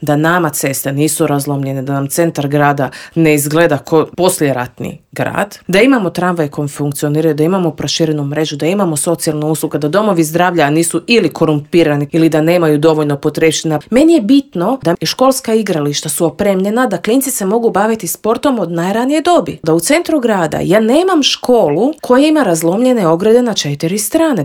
U intervjuu Media servisa ugostili smo jedne od glasnijih kritičara Tomislava Tomaševića i Možemo - nezavisnu zastupnicu u Skupštini Grada Zagreba Dinu Dogan i vijećnika u Vijeću Gradske četvrti Črnomerec Vedrana Jerkovića s kojima smo prošli kroz gradske teme.